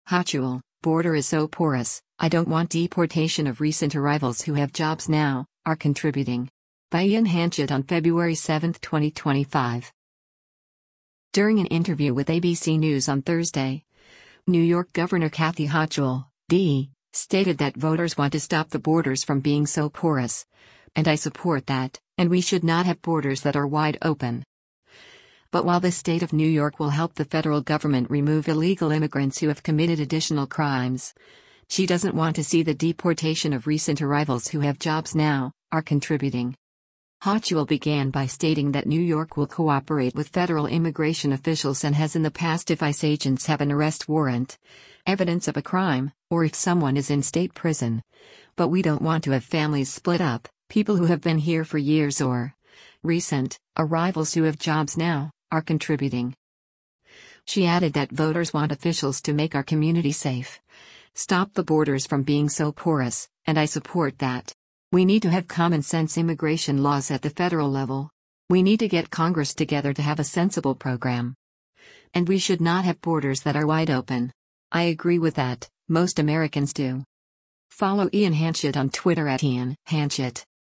During an interview with ABC News on Thursday, New York Gov. Kathy Hochul (D) stated that voters want to “stop the borders from being so porous, and I support that. … And we should not have borders that are wide open.” But while the state of New York will help the federal government remove illegal immigrants who have committed additional crimes, she doesn’t want to see the deportation of recent arrivals “who have jobs now, are contributing.”